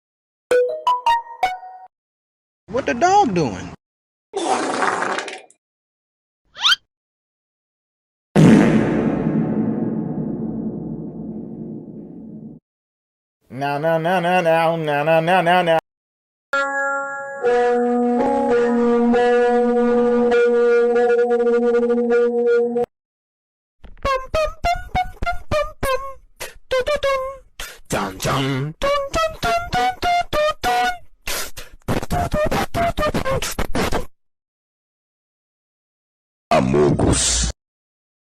Goofy Ahh Sound Effects - Botón de Efecto Sonoro